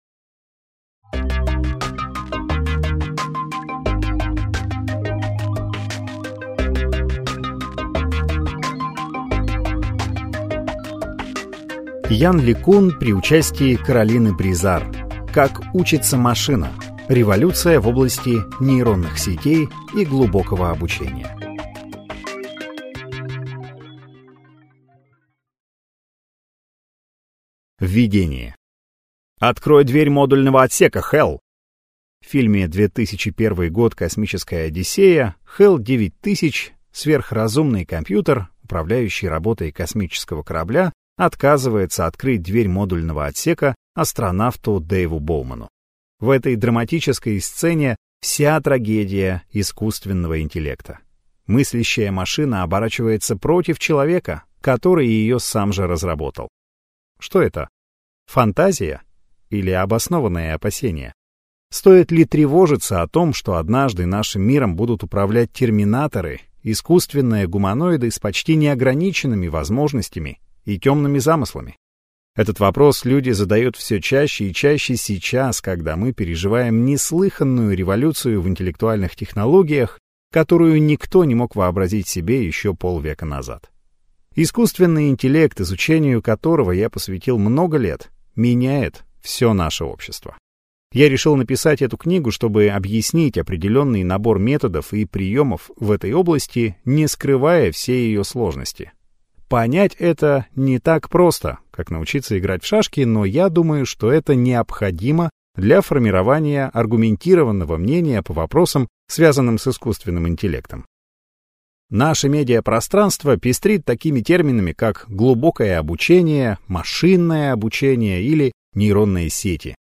Аудиокнига Как учится машина. Революция в области нейронных сетей и глубокого обучения | Библиотека аудиокниг